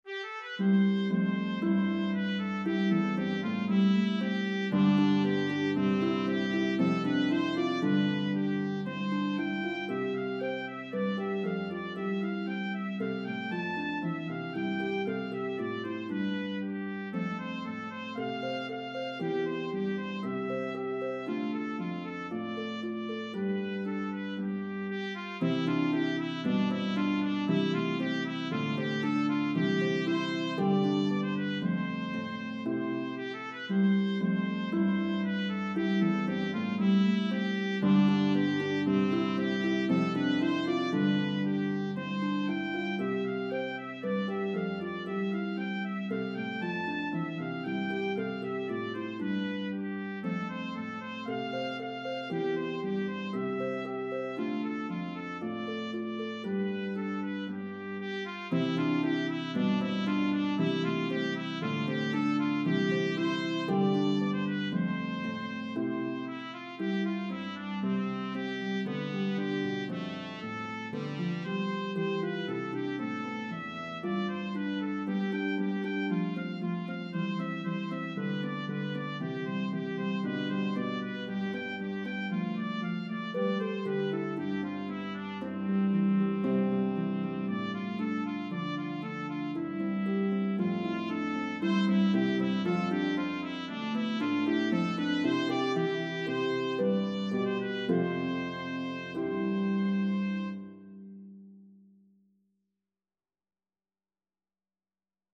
Harp and Trumpet in B-flat version